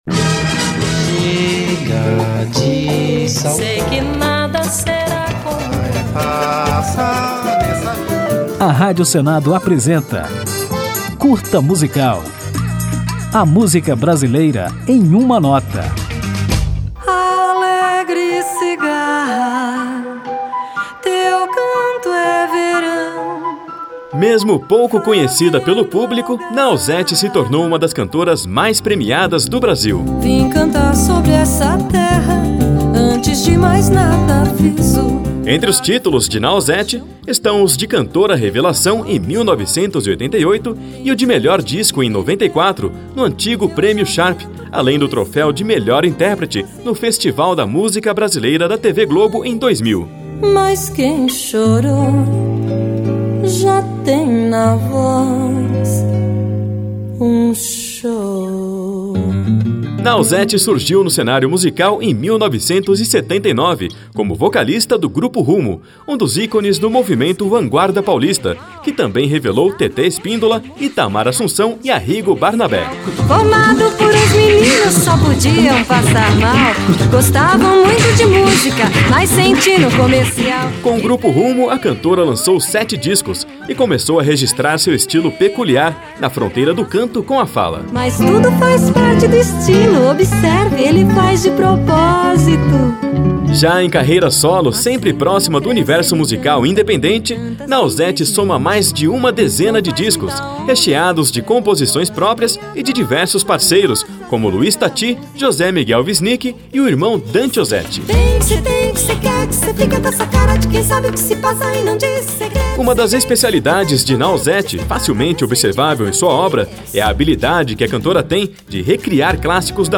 Aperte o play, conheça um pouco mais sobre a cantora e ainda desfrute a música Capitu, sucesso de Ná Ozzetti em 1999.